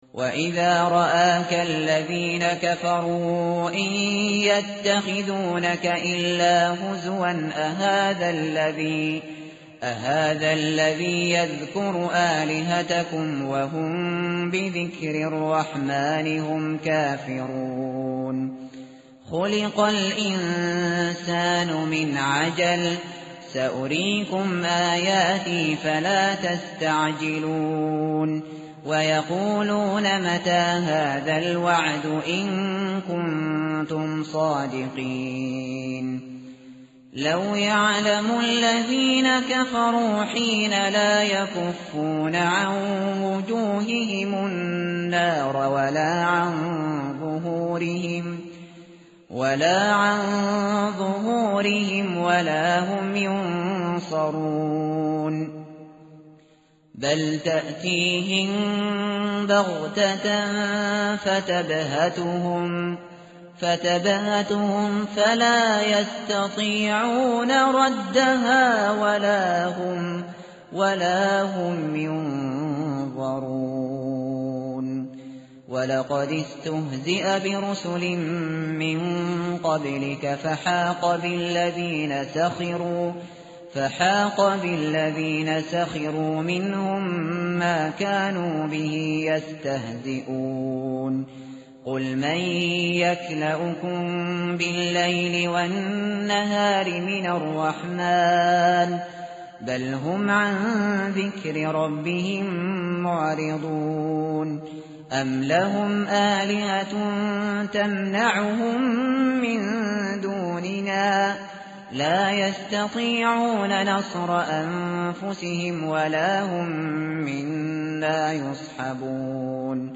tartil_shateri_page_325.mp3